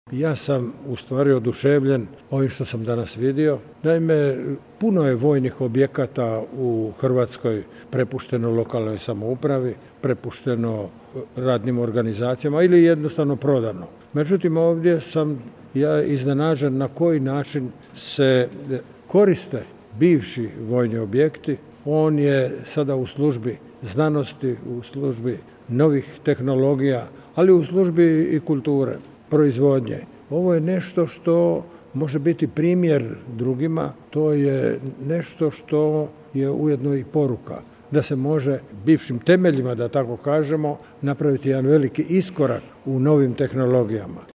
Tu je, na prostoru bivše vojarne, u izjavi za medije izrazio oduševljenje viđenim. Pohvalio je razvoj Međimurja uz korištenje europskog novca.